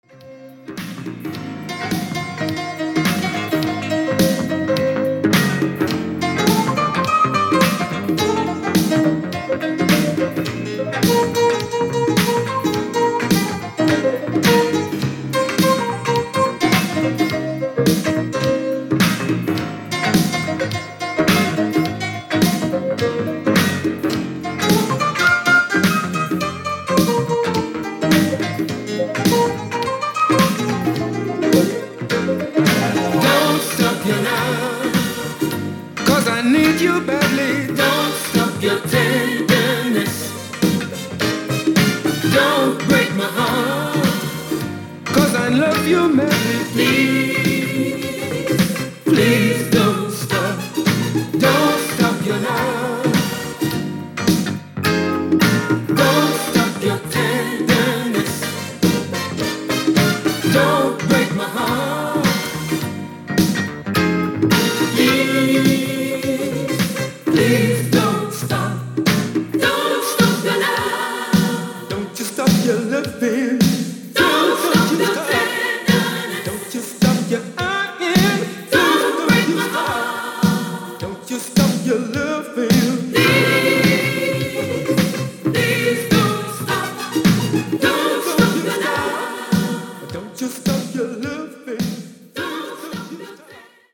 UKらしい洗練されたサウンドのイメージですね！！！